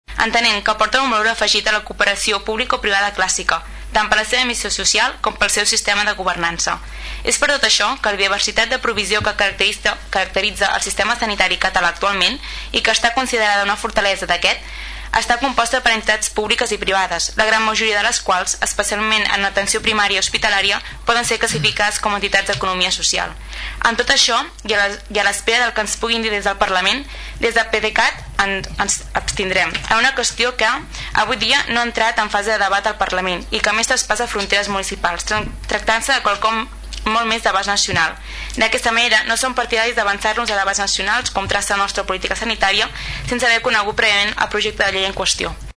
La regidora de salut, del PDeCAT, Bàrbara Vergés, considera que el projecte pretén regular les formules d’actuació del sistema sanitari. El grup es va abstenir a l’espera que entri a debat en el parlament.